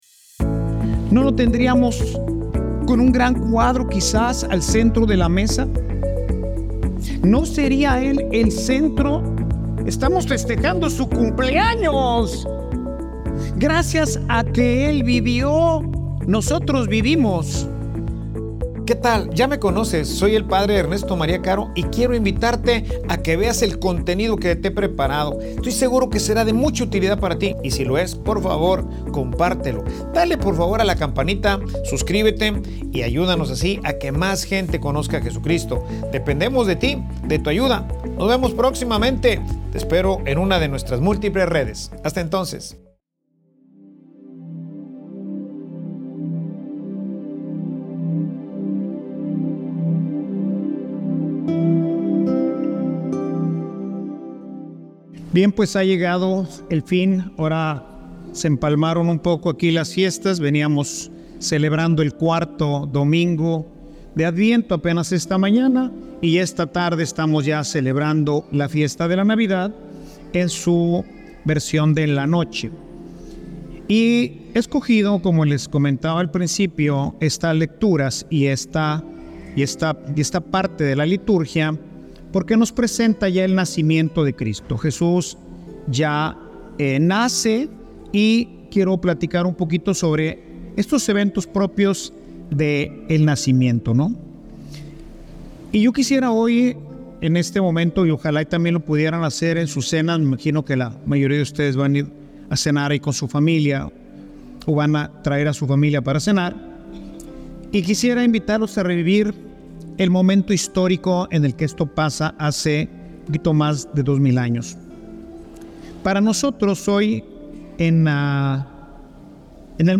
Homilia_Una_promesa_de_amor_y_salvacion.mp3